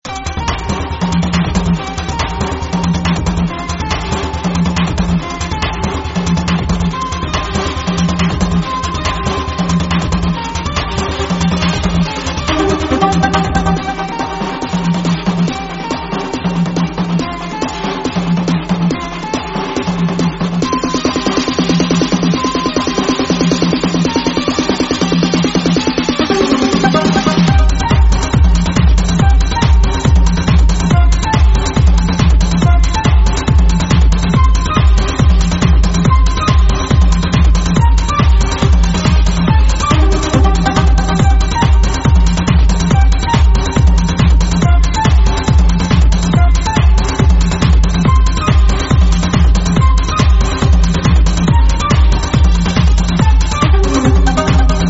Calidad de la muestra (48kbps) ⬅Dale click al Play